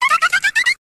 418Cry.wav